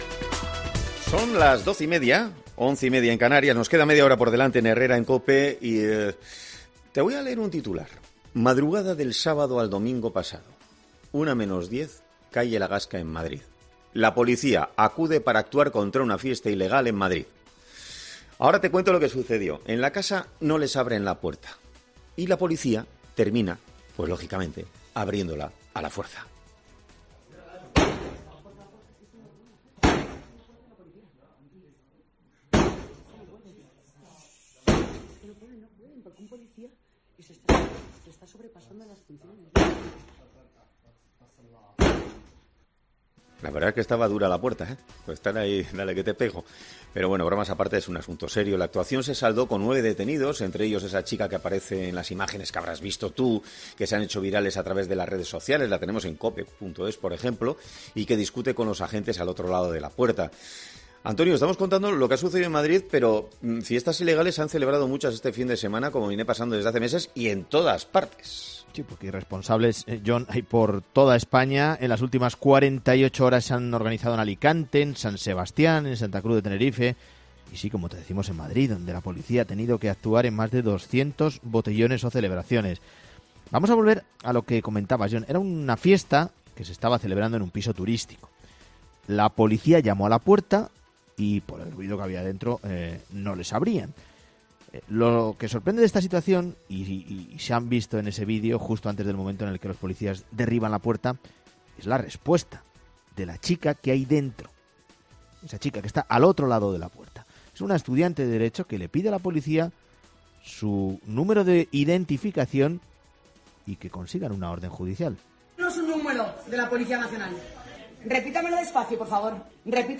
Una abogada penalista responde a esta cuestión tras la polémica de los policías que tiraron la puerta abajo en una fiesta ilegal de Madrid